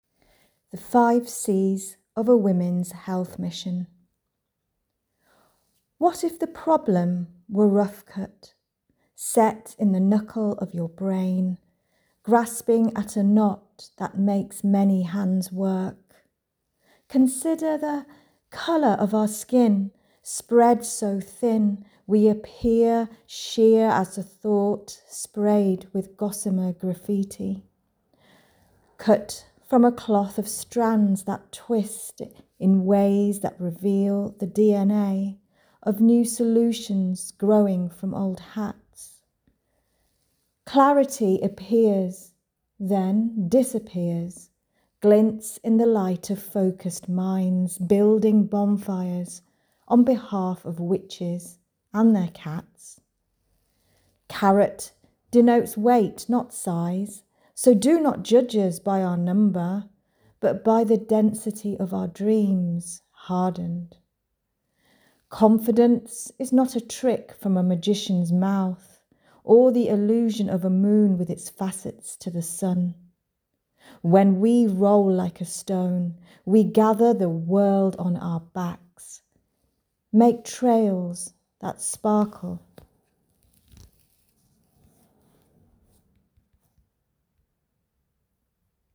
poem_whm.m4a